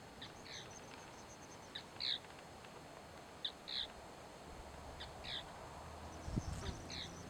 Churrín Ceja Blanca (Scytalopus superciliaris)
Nombre en inglés: White-browed Tapaculo
Condición: Silvestre
Certeza: Vocalización Grabada